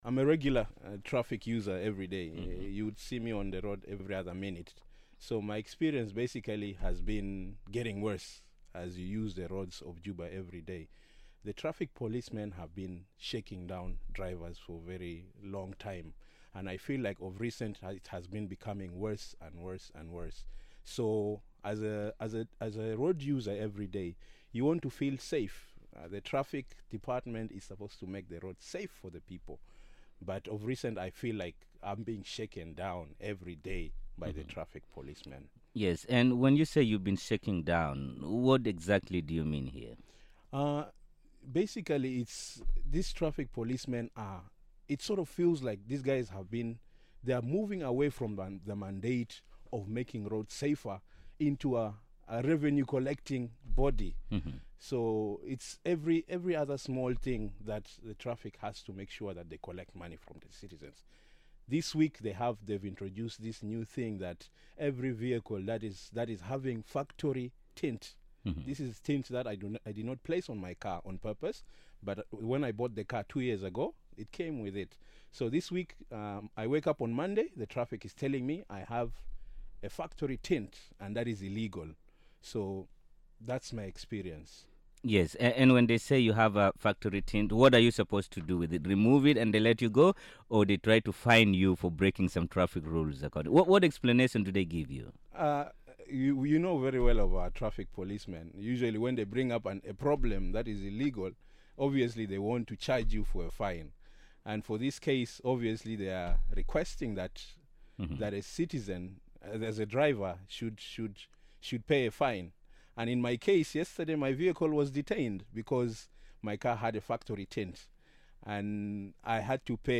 We spoke to one frustrated citizen to share his experience of his encounter with a traffic officer.